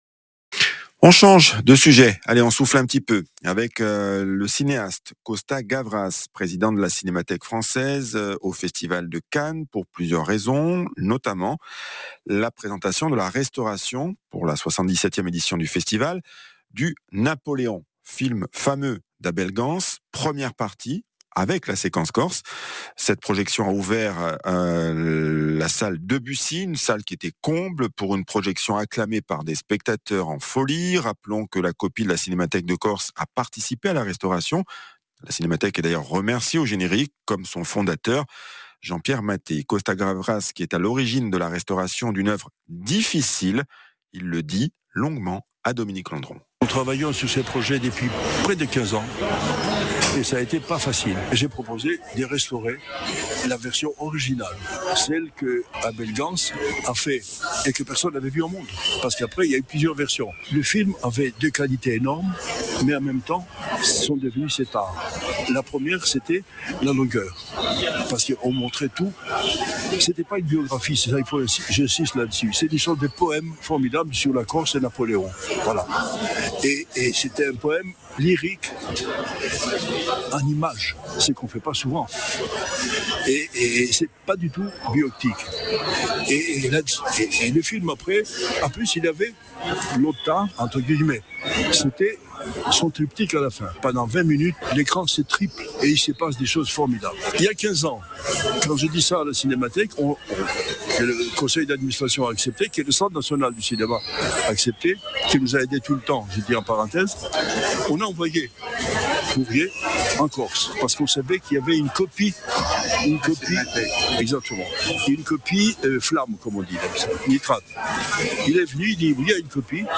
Le réalisateur Costa Gavras, président de la Cinémathèque française, évoque au micro de RCFM la restauration du "Napoléon" d'Abel Gance, et la projection de la première partie (3h40) en ouverture de Cannes Classics.